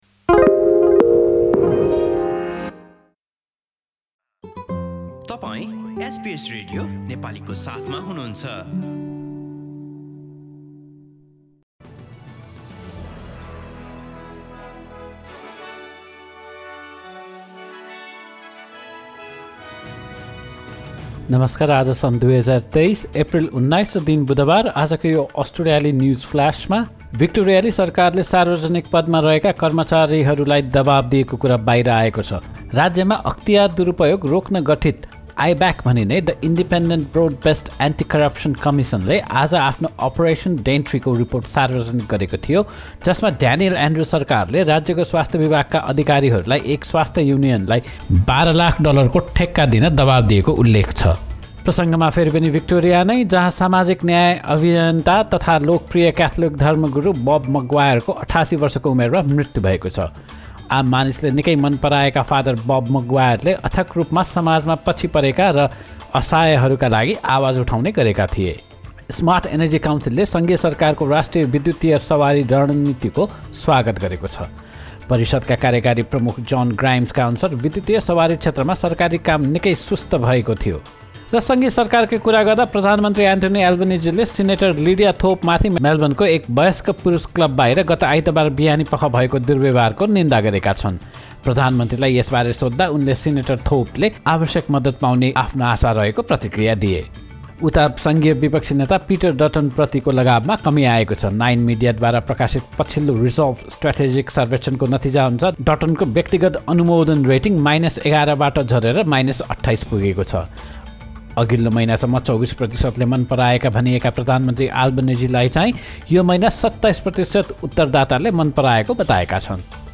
एसबीएस नेपाली अस्ट्रेलिया न्युजफ्लास: बुधवार, १९ एप्रिल २०२३